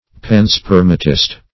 Search Result for " panspermatist" : The Collaborative International Dictionary of English v.0.48: Panspermatist \Pan*sper"ma*tist\, Panspermist \Pan"sper`mist\, n. (Biol.)